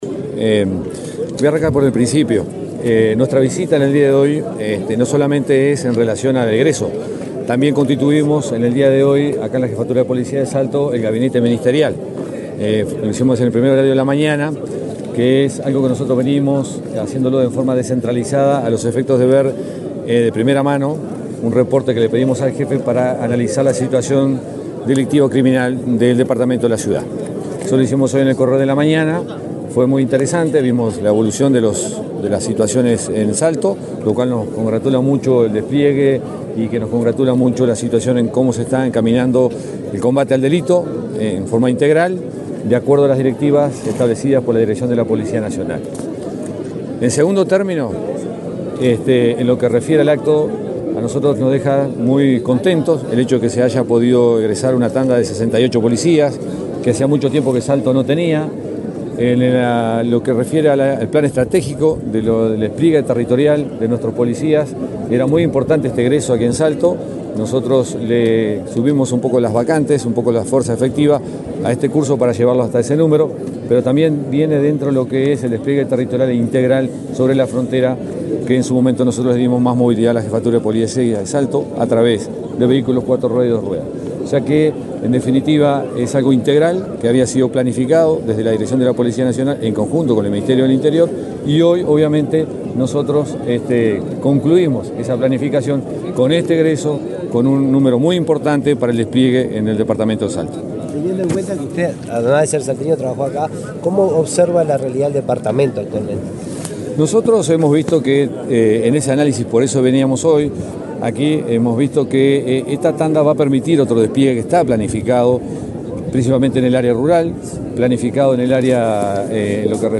Declaraciones del director de la Policía Nacional, José Azambuya
El director de la Policía Nacional, José Azambuya, dialogó con la prensa en la Jefatura de Policía de Salto, donde se realizó la ceremonia de egreso